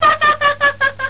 ChunLi-laugh.wav